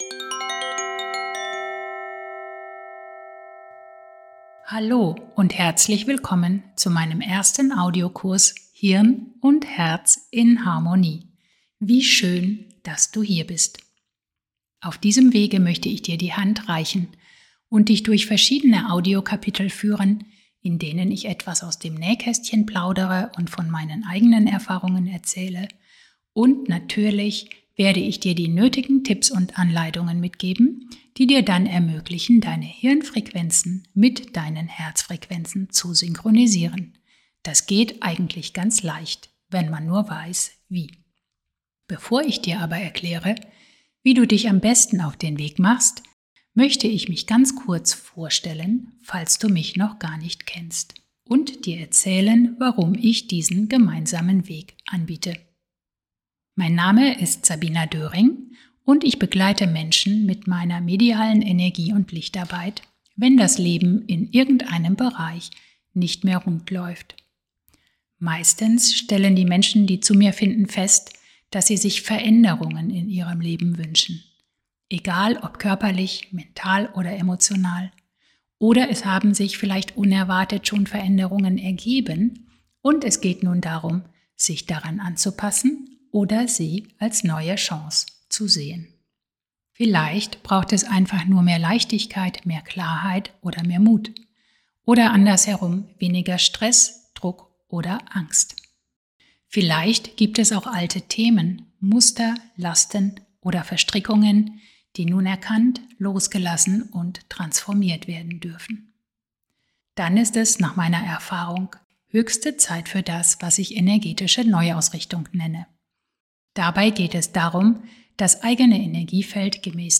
Dein Audiokurs im Podcast Format
In den einzelnen Audios des Kurses erfährst Du alles Wissenswerte und erhältst geführte Übungen zum Mitmachen, sodass Du direkt loslegen kannst, wenn Du magst.